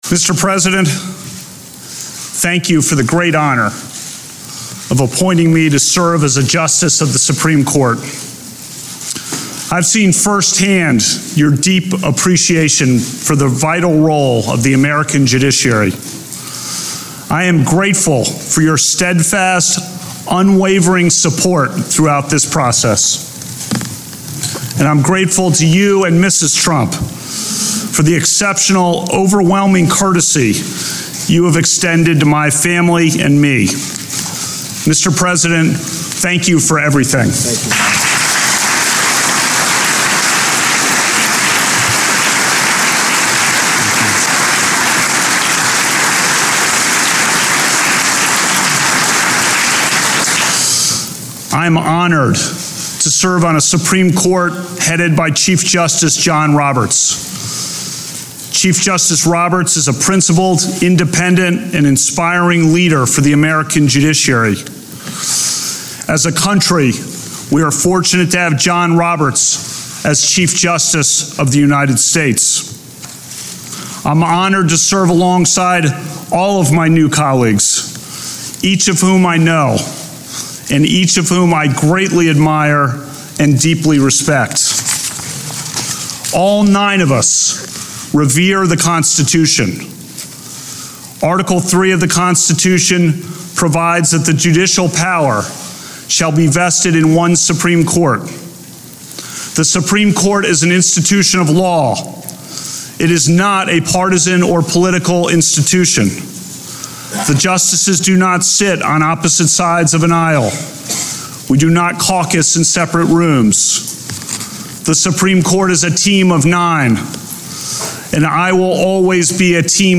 Brett Kavanaugh USSC Swearing-In Ceremony Speech
brettkavanaughswearinginARXE.mp3